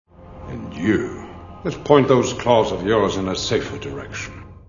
X-Men Movie Sound Bites